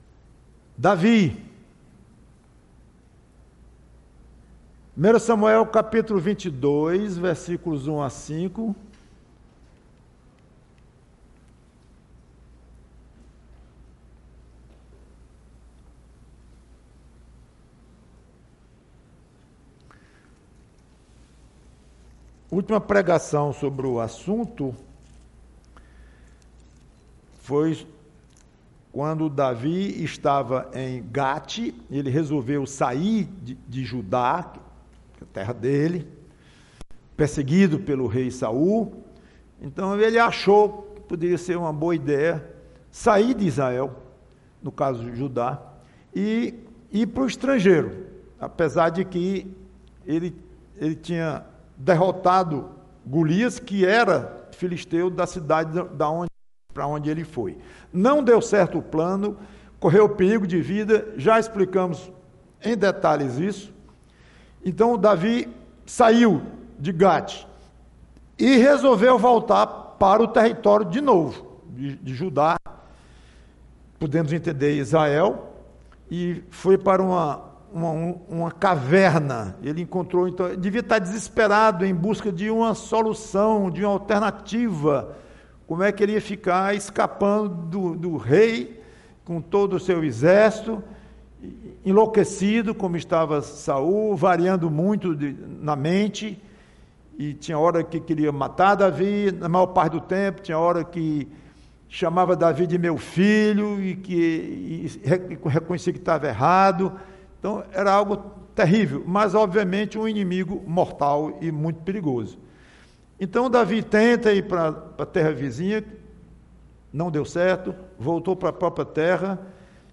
PREGAÇÃO Enfrente o perigo!